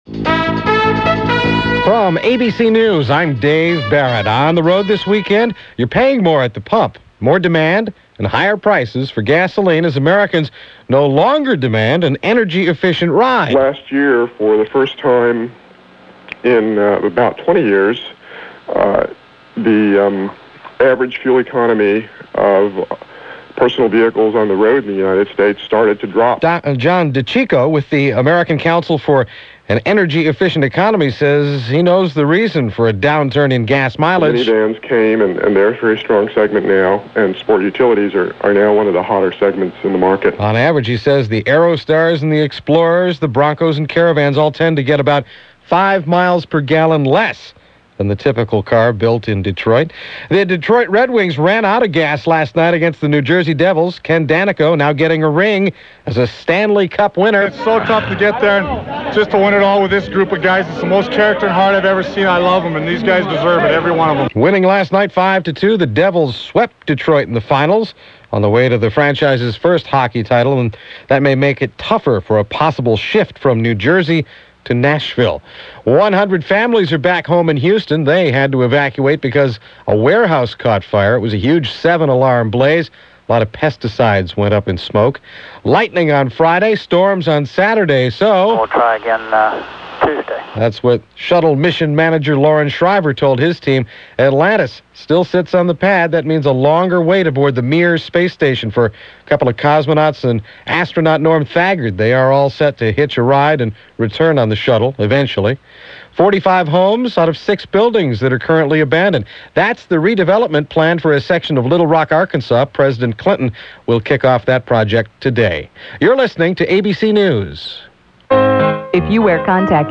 . or click on the link here for Audio Player – ABC Radio News – June 25, 1995 – Gordon Skene Sound Collection.
And that’s a slice of what went on this June 25th in 1995 as reported by ABC Radio News.